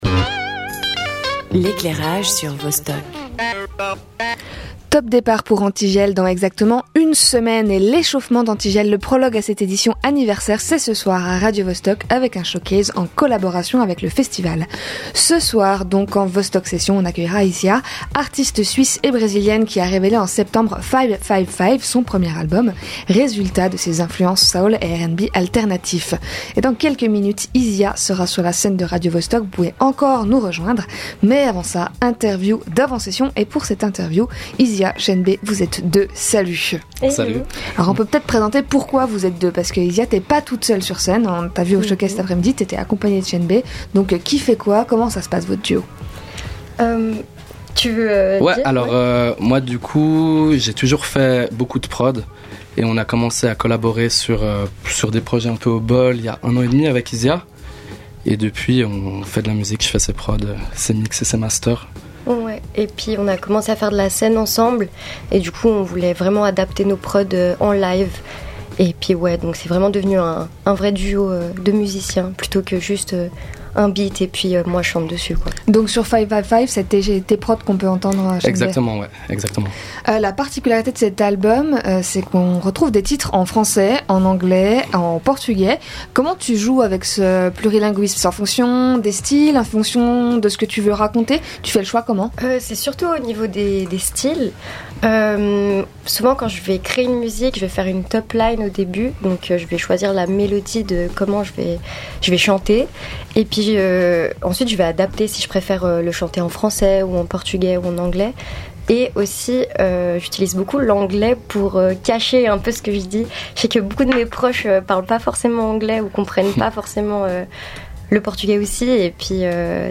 interview
Animation